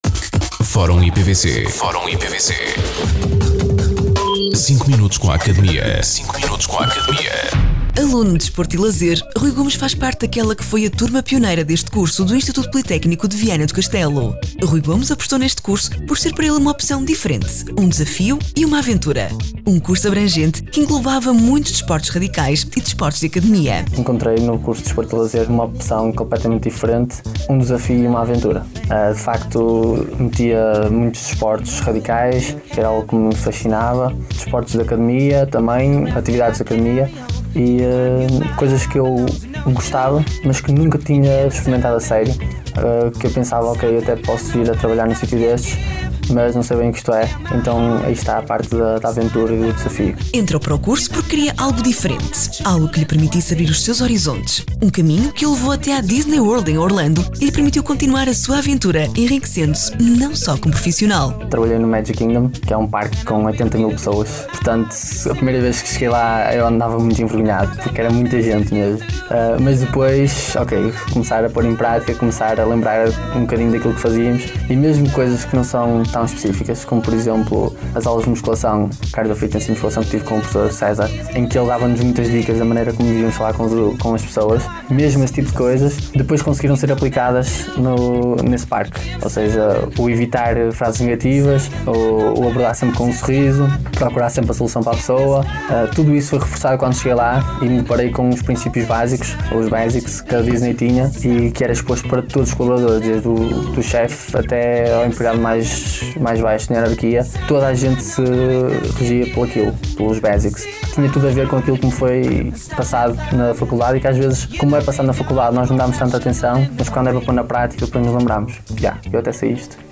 O Instituto Politécnico de Viana do Castelo [IPVC] tem mais um espaço radiofónico a ser transmitido, desta feita, na Rádio Caminha [RJC FM], em 106.2.
Entrevistados: